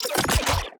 Reloading_begin0011.ogg